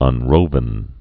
(ŭn-rōvən)